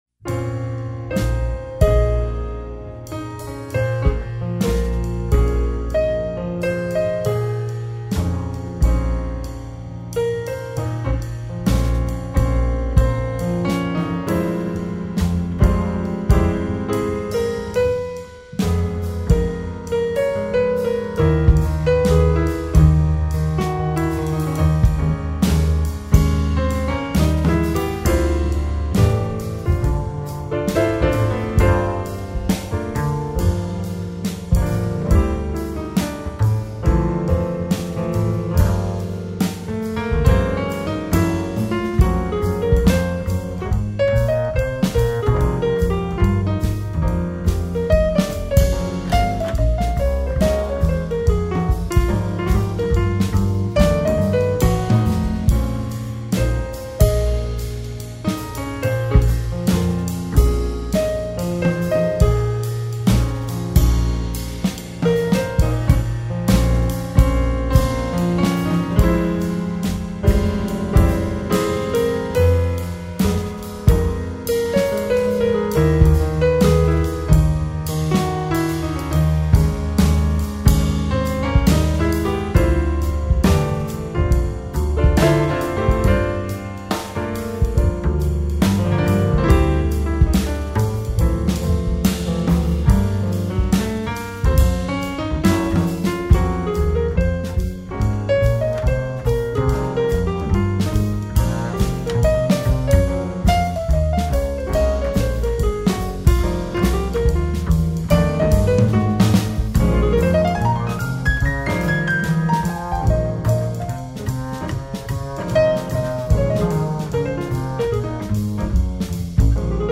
Contemporary piano trio jazz.